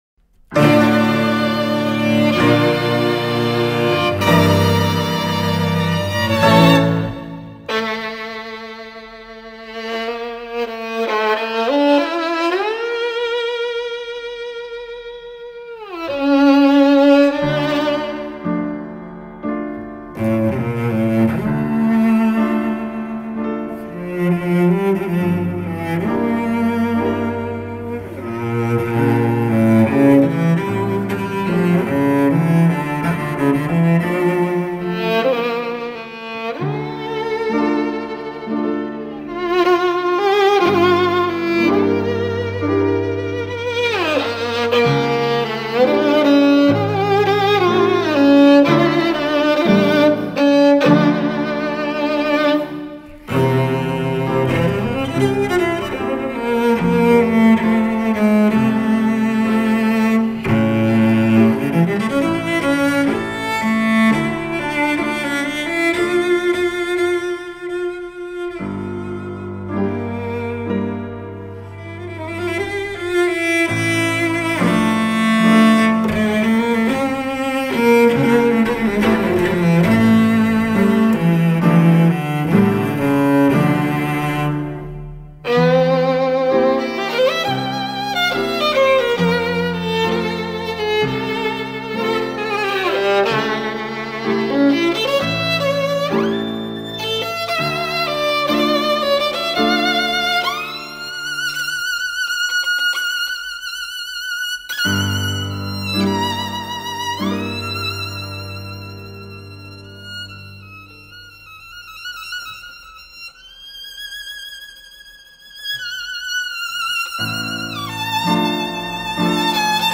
Semi-Classic
Czardas (바이얼린, 첼로 협주)